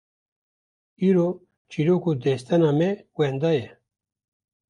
Pronounced as (IPA)
/t͡ʃiːˈɾoːk/